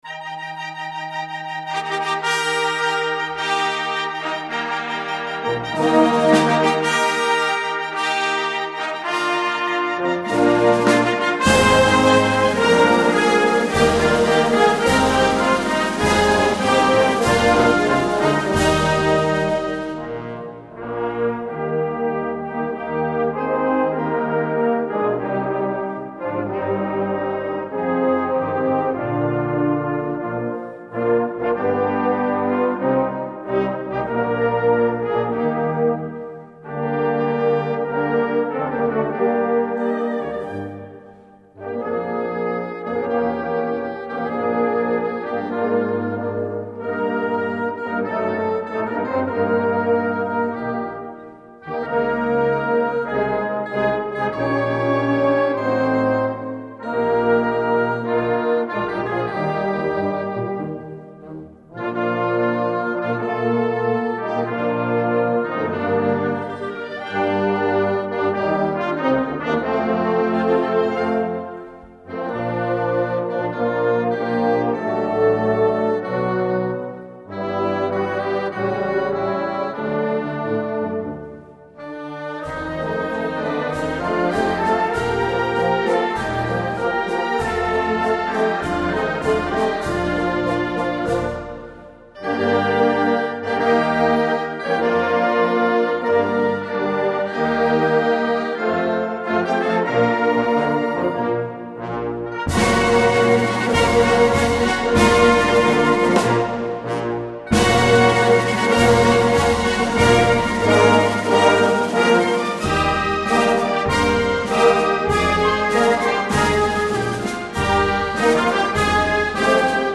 hino_olimpico_instrumental.mp3